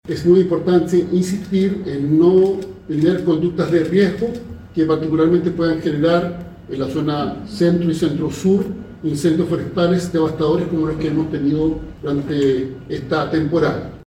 Por su parte, el ministro del Interior, Álvaro Elizalde, hizo un llamado a la responsabilidad ciudadana frente a conductas de riesgo que podrían provocar incendios forestales tan graves como los ocurridos anteriormente en las regiones de Ñuble y Biobío.
cu-ola-de-calor-ministro.mp3